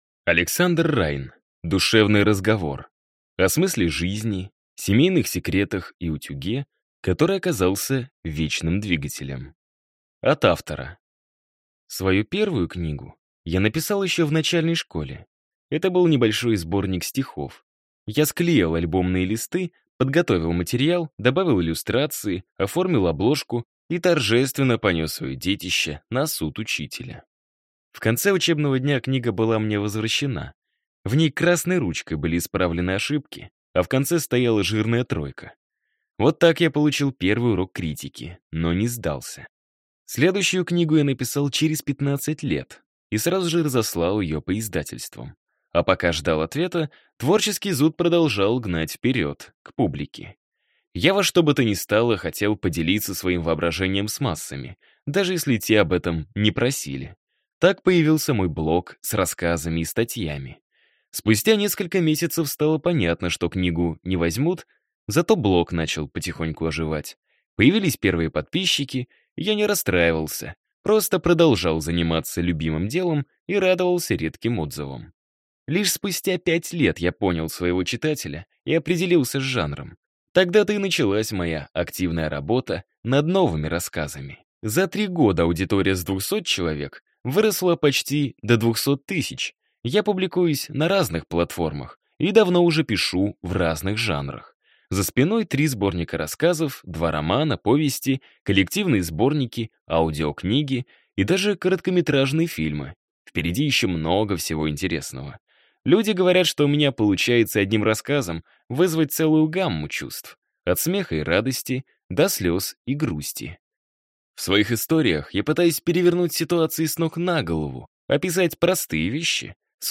Аудиокнига Душевный разговор. О смысле жизни, семейных секретах и утюге, который оказался вечным двигателем | Библиотека аудиокниг